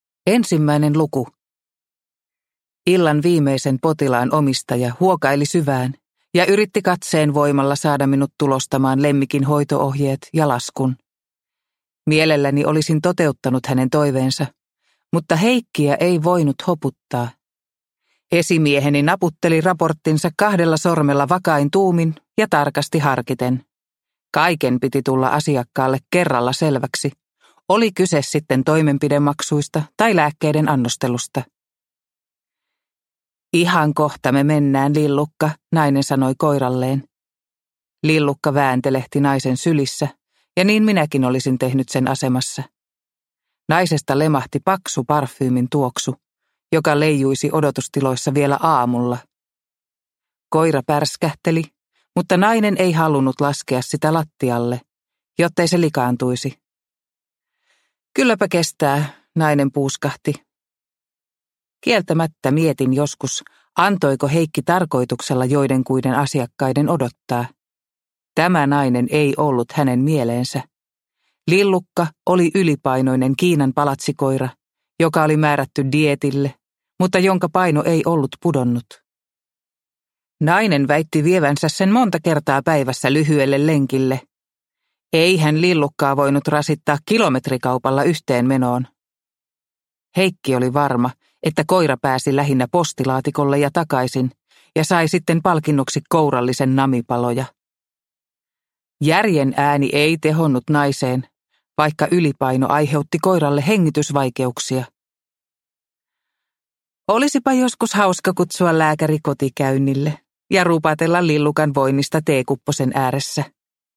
Sumulaakson kartano – Ljudbok – Laddas ner